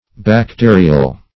Bacterial \Bac*te"ri*al\, a.